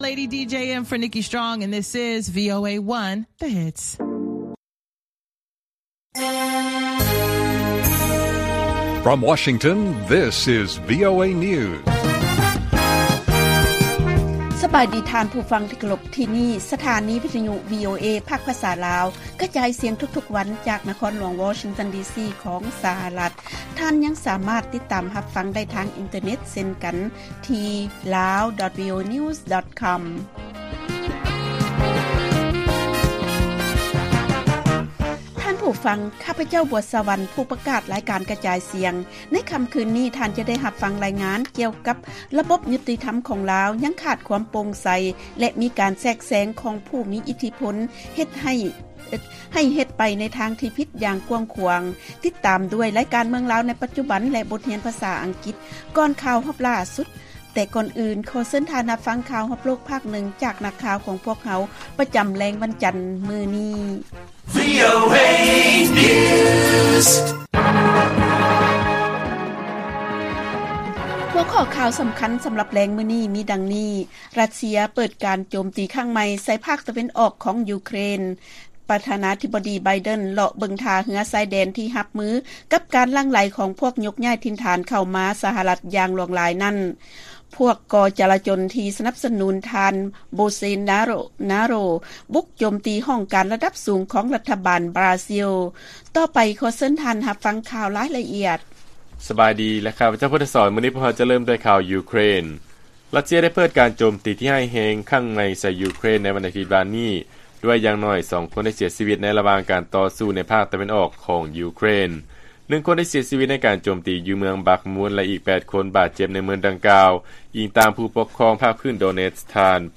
ລາຍການກະຈາຍສຽງຂອງວີໂອເອ ລາວ: ຣັດເຊຍ ເປີດການໂຈມຕີຄັ້ງໃໝ່ ໃສ່ພາກຕາເວັນອອກ ຂອງ ຢູເຄຣນ